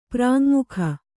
♪ prājm``mukha